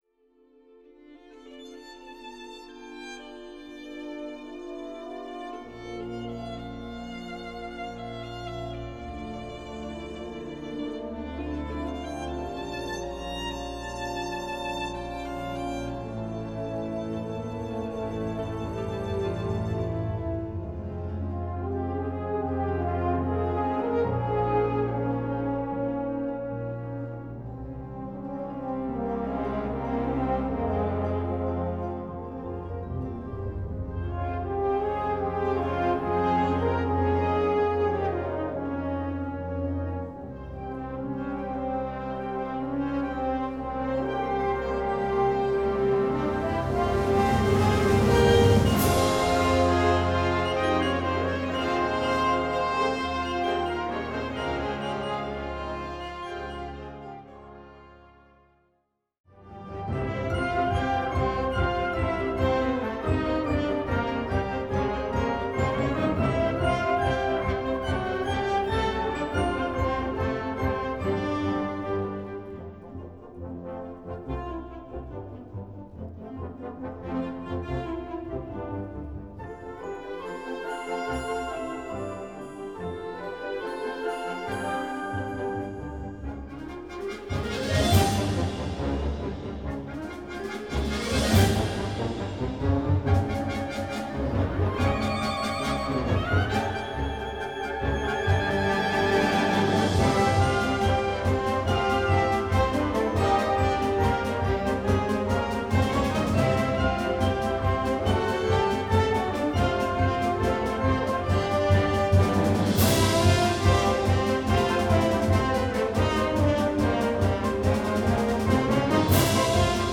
Partitions pour orchestre, ou - d'harmonie, ou - fanfare.